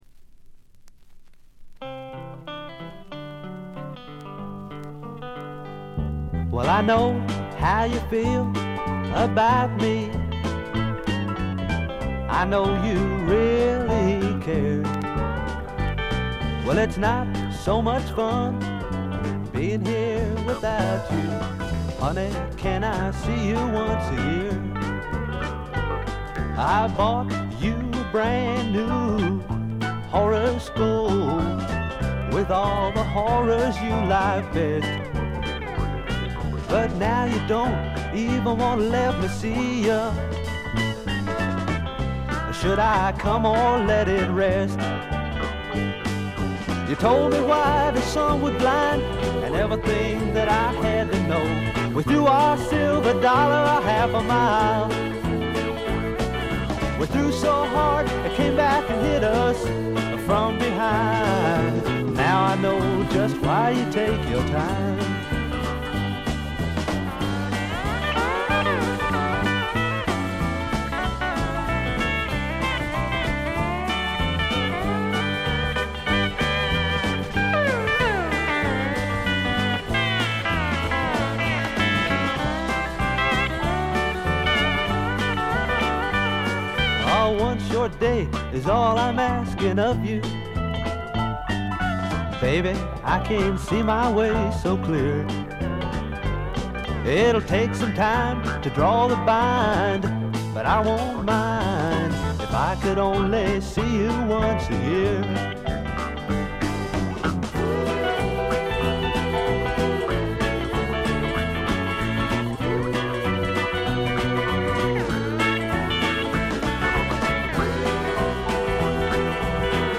軽微なバックグラウンドノイズ。散発的なプツ音が少し。
試聴曲は現品からの取り込み音源です。